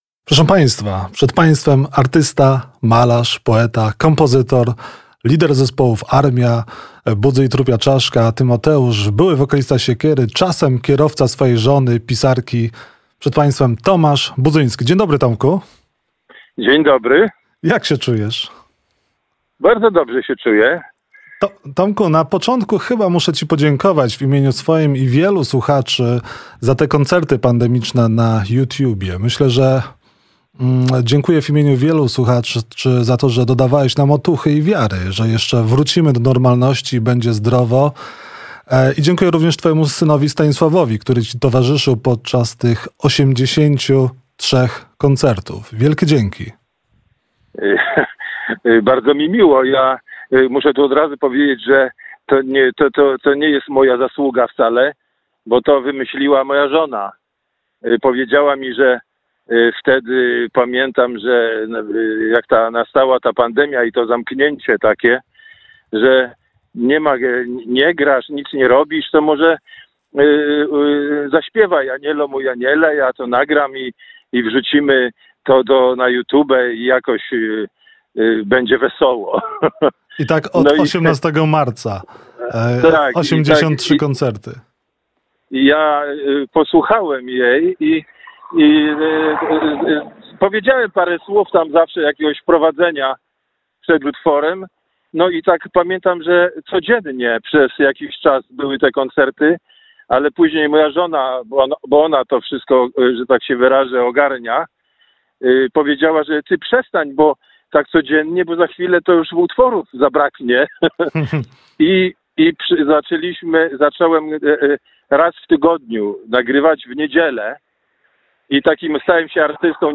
Rozmowa telefoniczna.